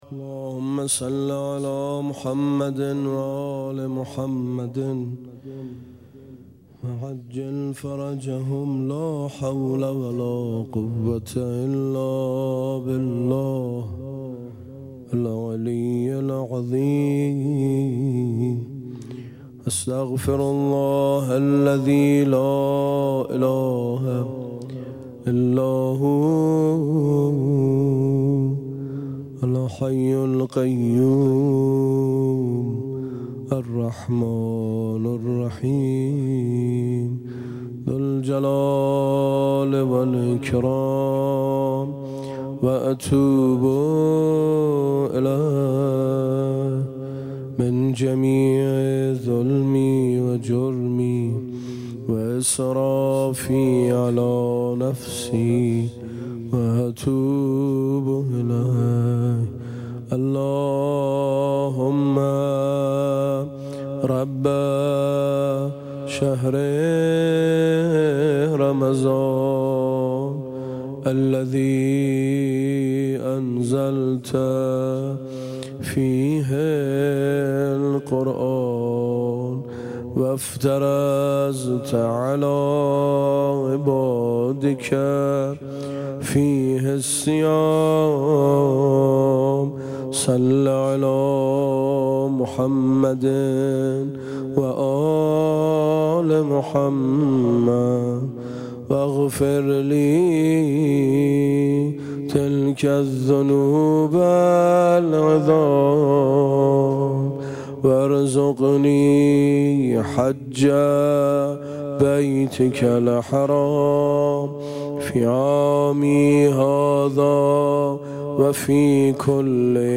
مناجات با امام زمان (عج)
شب هشتم ماه رمضان